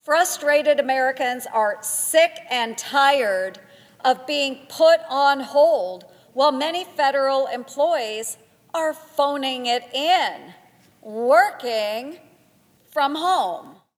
Ernst asked during remarks on the Senate floor.